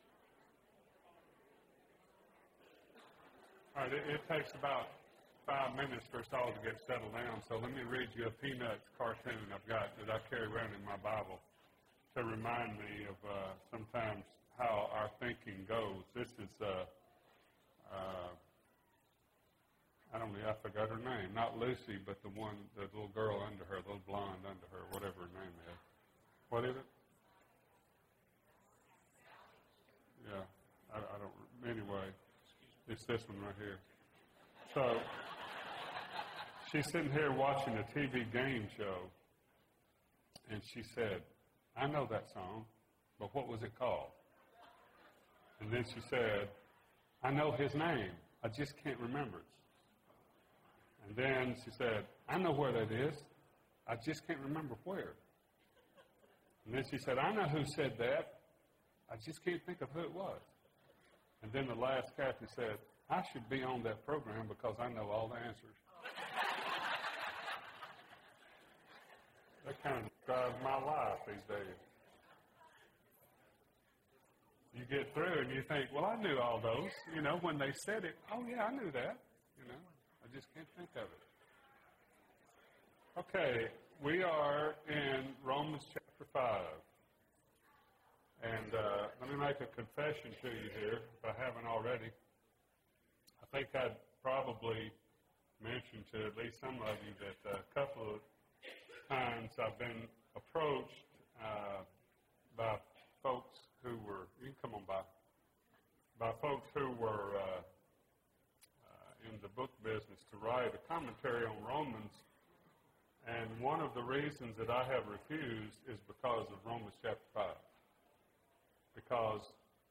Romans – Blessings for Humanity (14 of 24) – Bible Lesson Recording